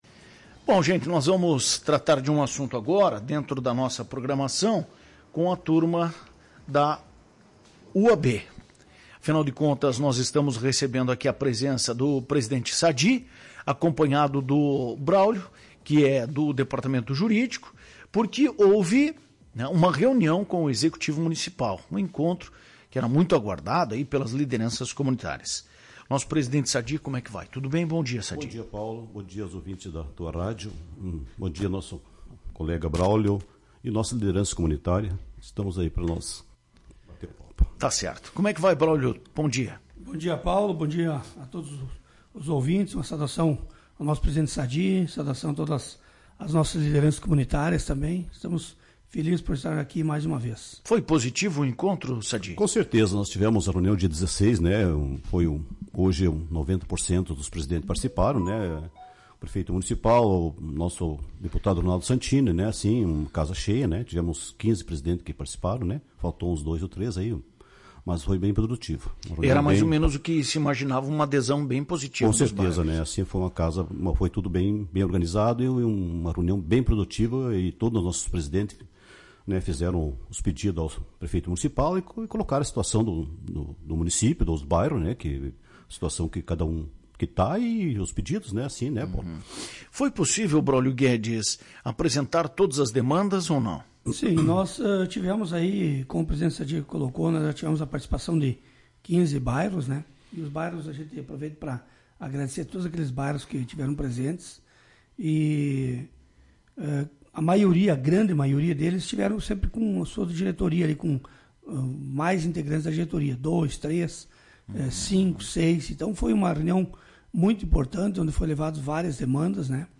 A reunião contou com a presença de 15 presidentes de bairros, revelando um engajamento significativo da comunidade — que clama por melhorias estruturais nos bairros. Ouça a entrevista.